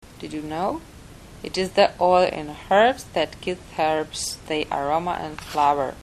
Nahrávka výslovnosti (*.MP3 soubor):